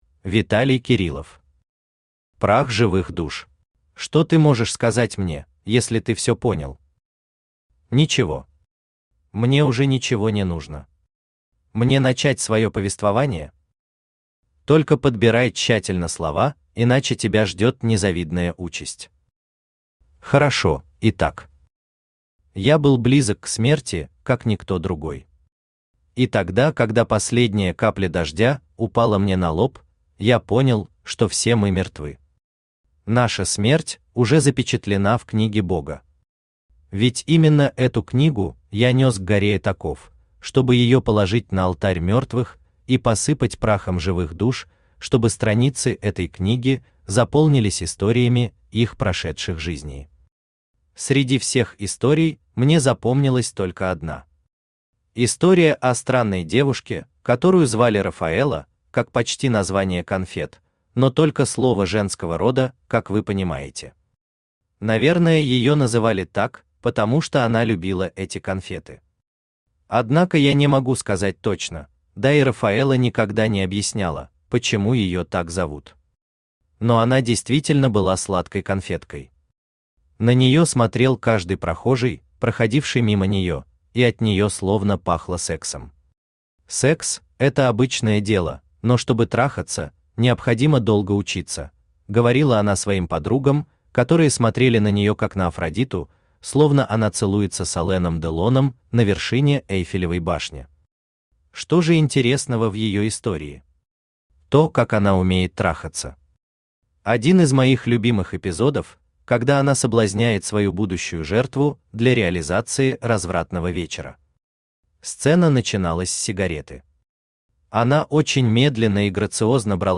Аудиокнига Прах живых душ
Содержит нецензурную брань.